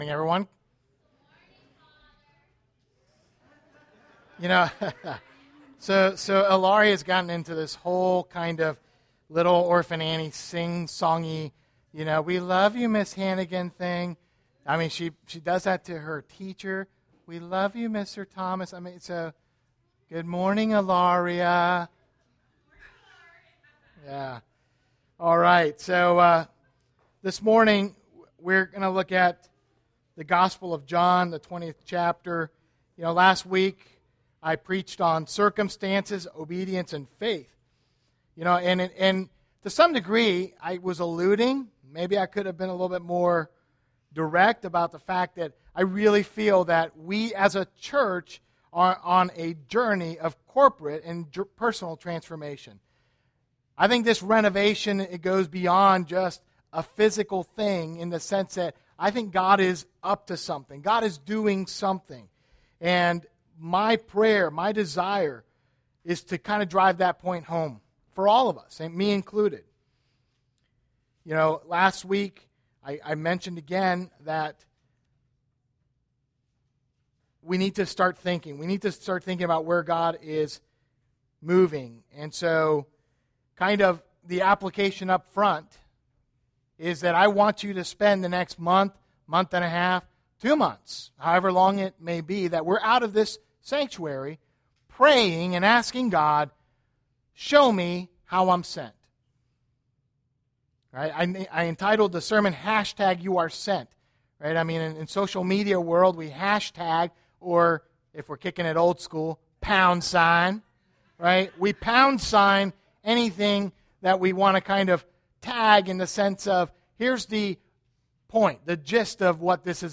First Baptist Sermons